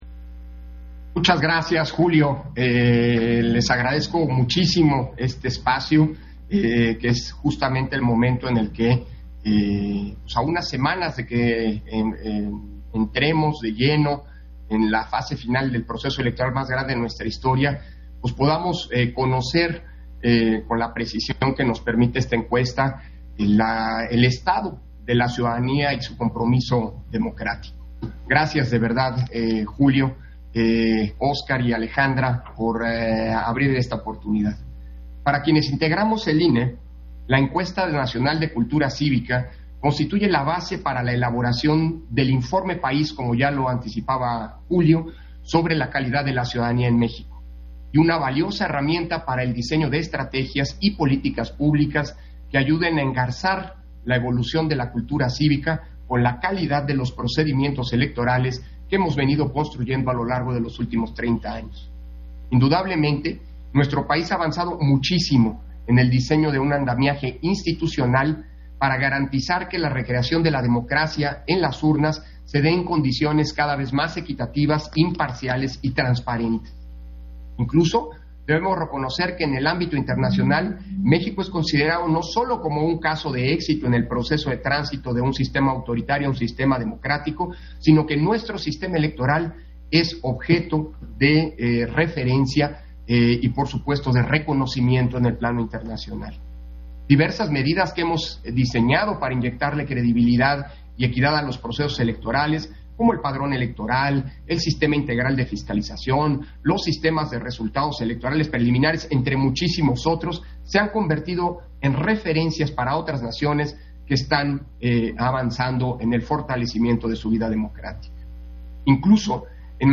Intervención de Lorenzo Córdova, en la presentación del informe de resultados de la Encuesta Nacional de Cultura Cívica (ENCUCI) 2020